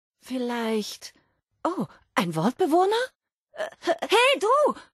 Wastelanders: Audiodialoge